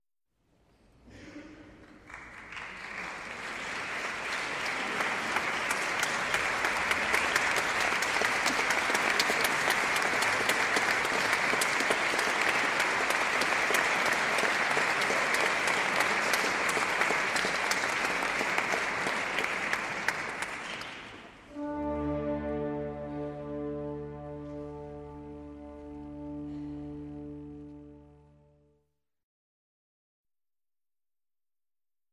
Звук начала концерта в консерватории
Общество, публика, люди